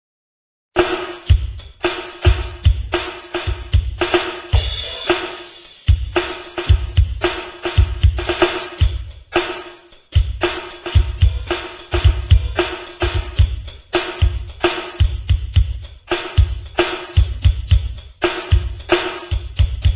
Drum Solo